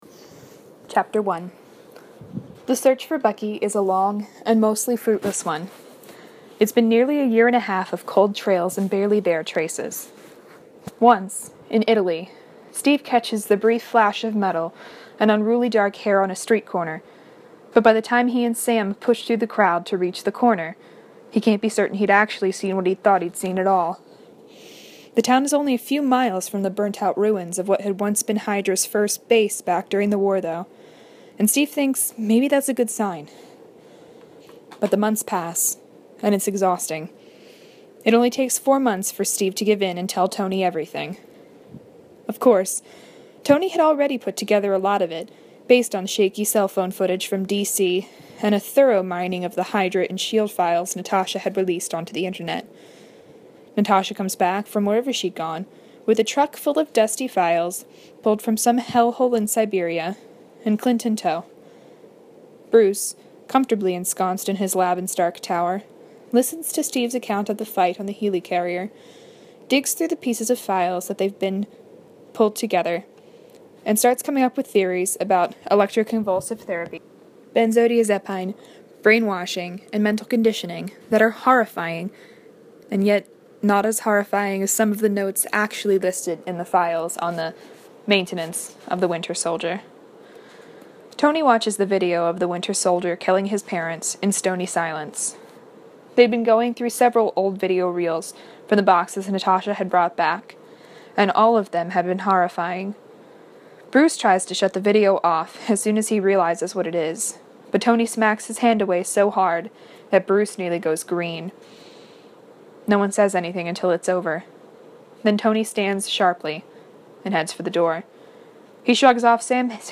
podfic